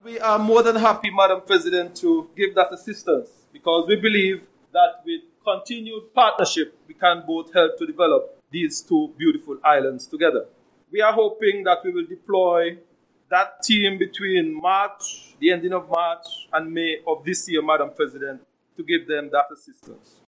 At the first Sitting of the Nevis Island Assembly for 2025, which took place on Thursday, March 6th, Minister of Works, Water Services, et. al., the Hon. Spencer Brand, shared the following announcement: